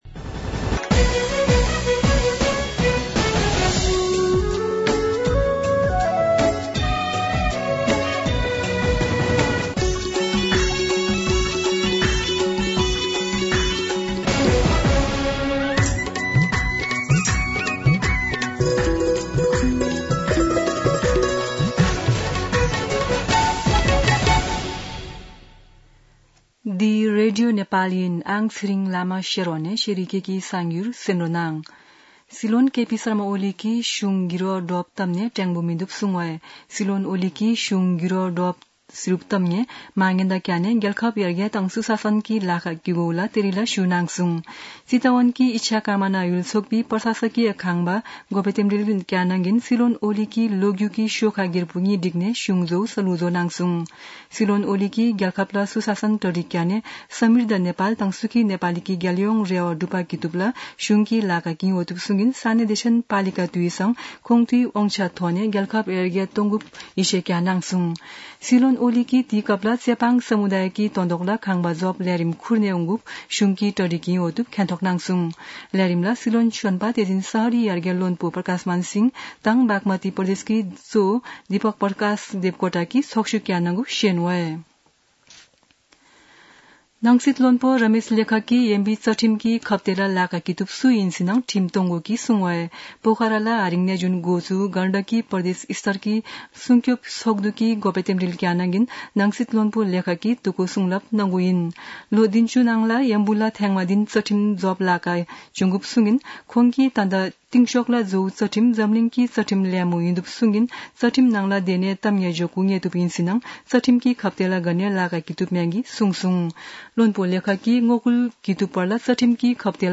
शेर्पा भाषाको समाचार : ५ वैशाख , २०८२
Sherpa-News-5.mp3